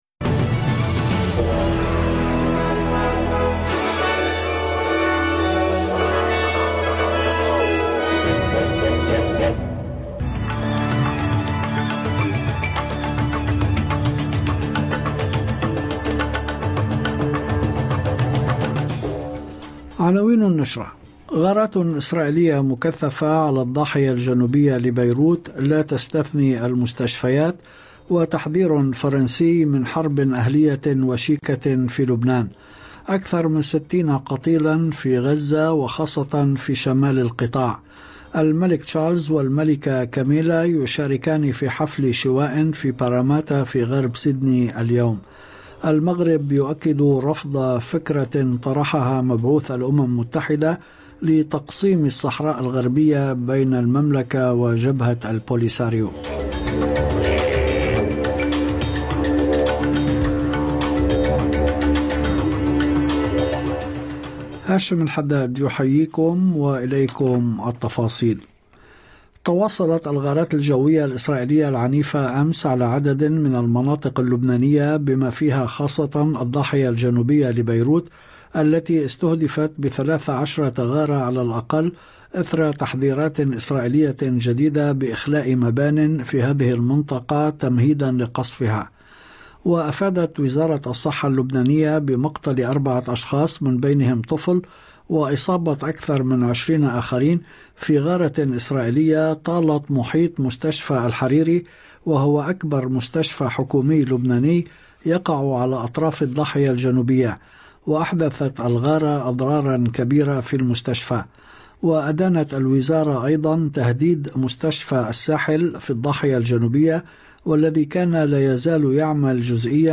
نشرة أخبار المساء 22/10/2024